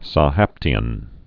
(sä-hăptē-ən) also Sha·hap·ti·an (shä-)